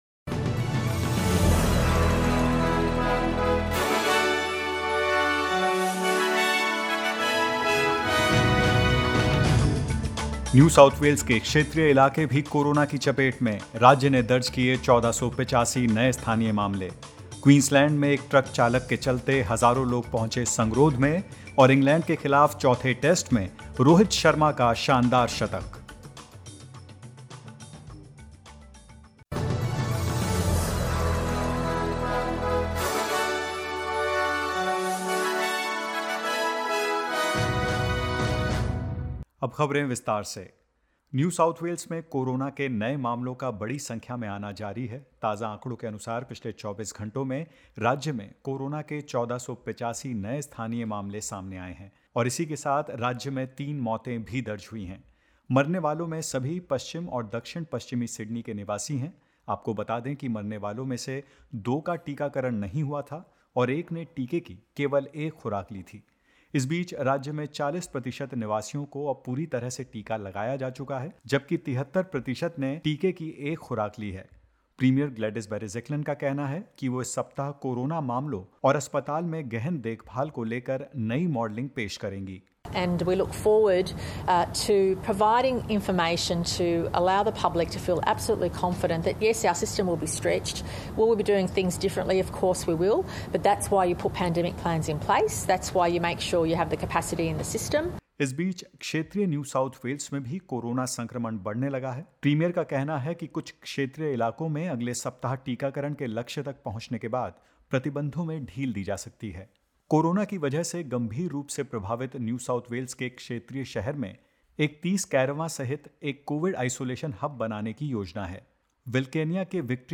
In this latest SBS Hindi News bulletin of Australia and India: NSW reaches 40 percent double-dose vaccination; Queensland reports one new local case and more.